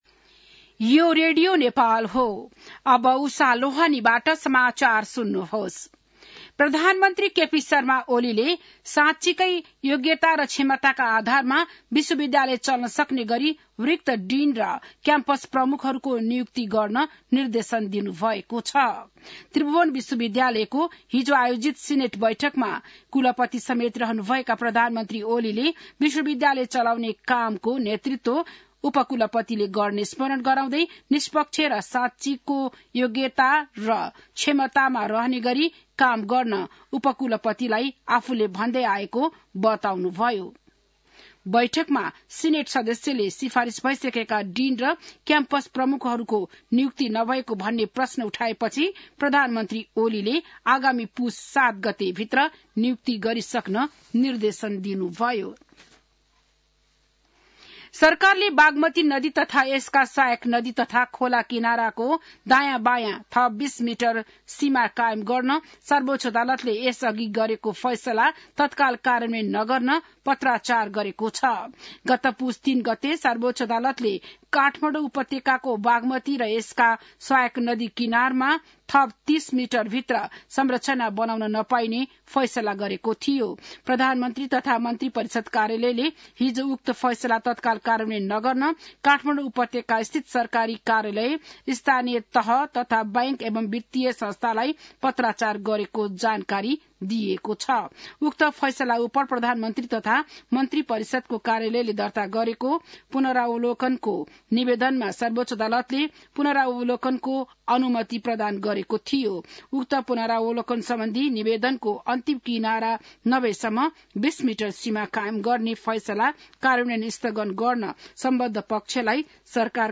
बिहान १० बजेको नेपाली समाचार : २९ मंसिर , २०८१